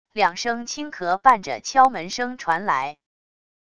两声轻咳伴着敲门声传来wav音频